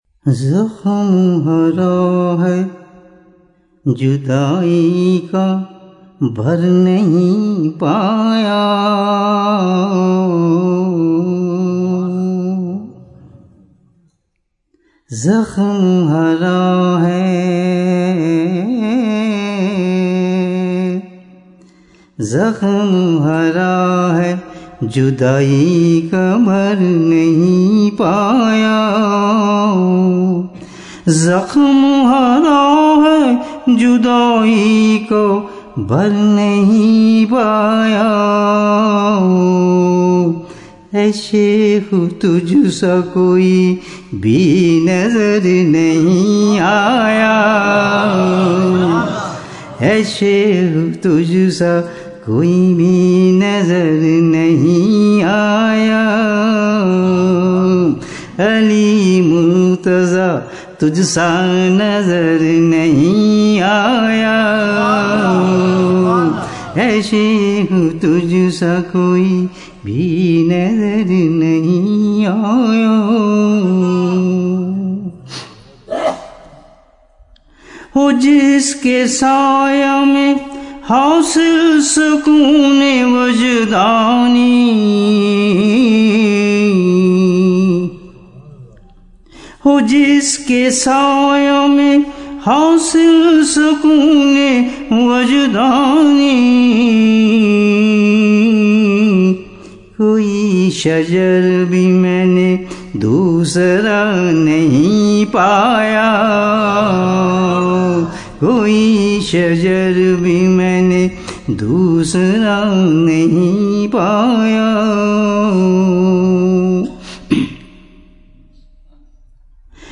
Subhanallah a beautiful Manqabat